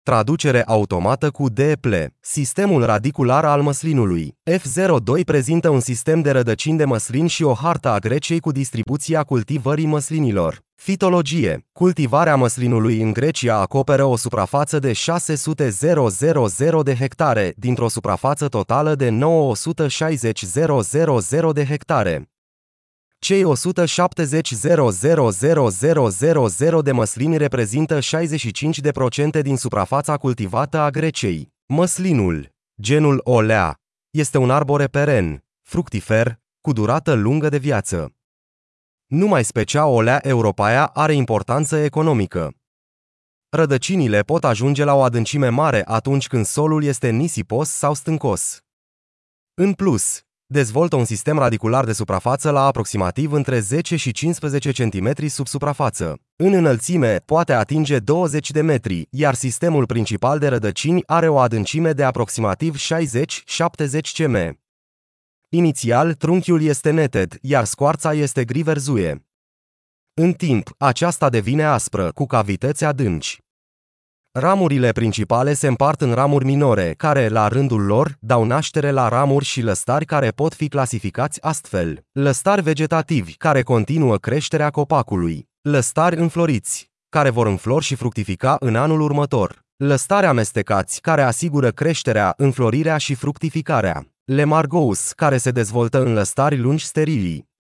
Tur ghidat audio